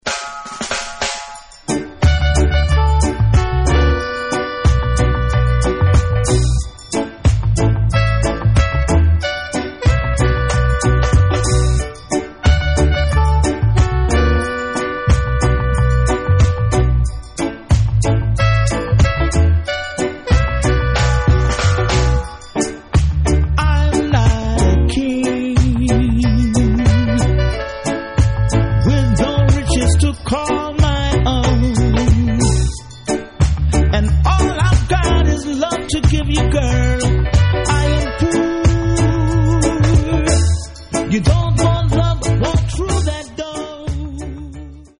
Reggae Ska Dancehall Roots Vinyl ...